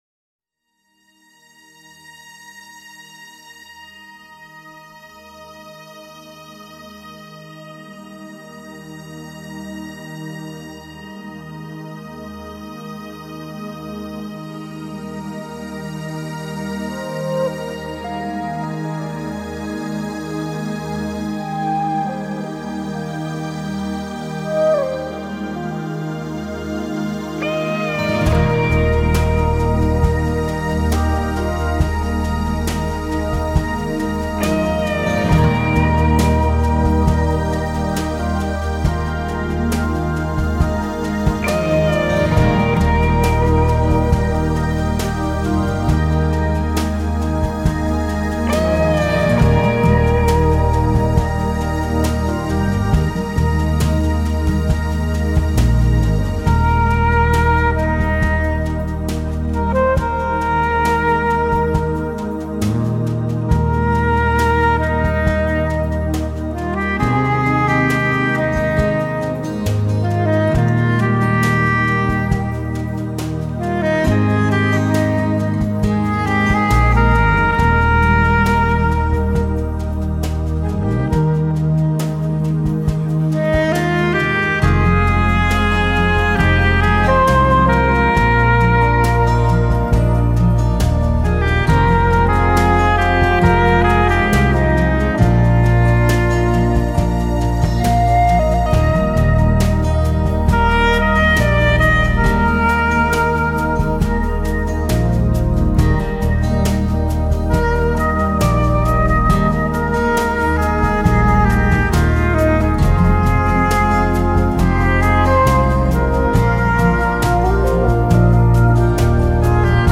专辑中所用的乐器包括了电子吉他、钢琴、长笛、竖琴、萨
克斯等，演奏出了一部壮丽、神秘的音乐组曲。